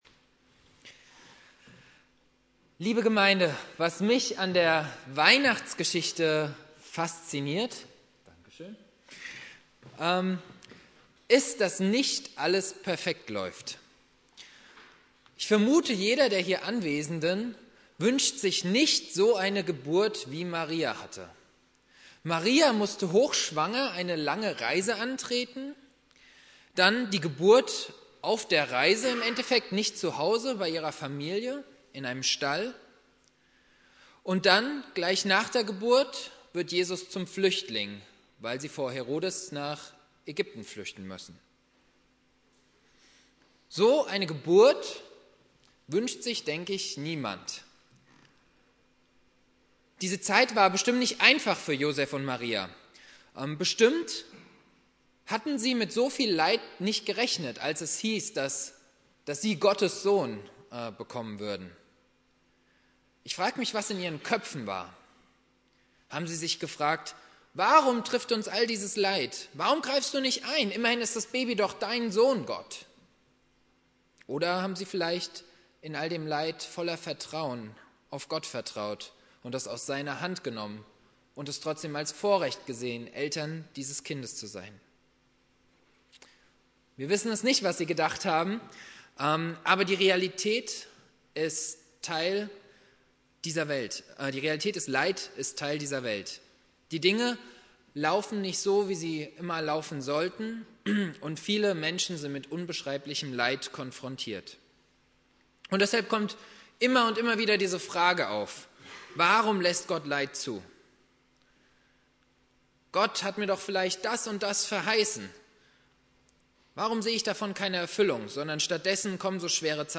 Predigt
2.Weihnachtstag Prediger